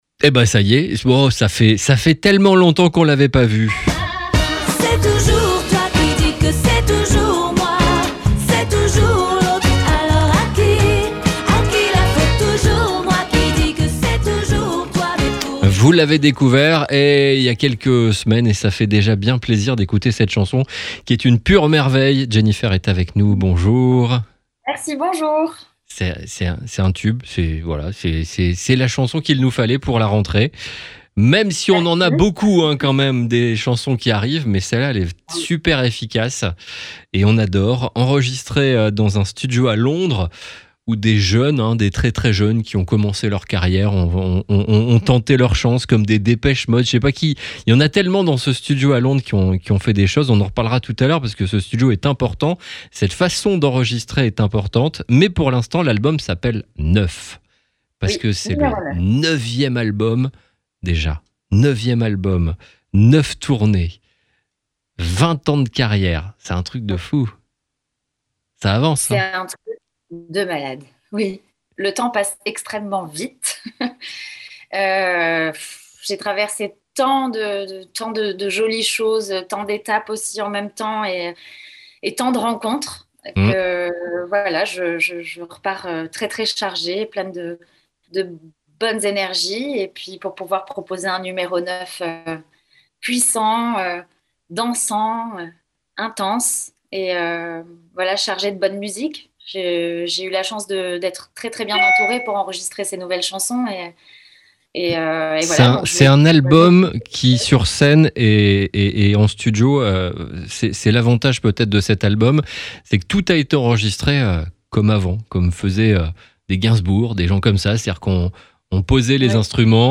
Jenifer était notre invité vendredi 2 septembre à 11h30 !